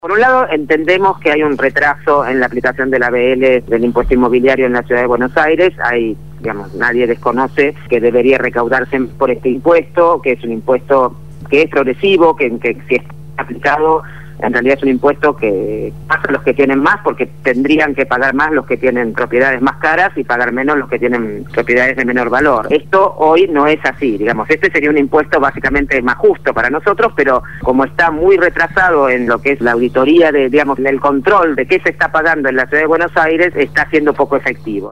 Gabriela Alegre, Legisladora porteña del Frente Para la Victoria (FPV) quien continuará su mandato a partir del próximo 10 de diciembre al haber sido electa el 10 de julio pasado,  habló esta mañana en el programa Punto de Partida de Radio Gráfica FM 89.3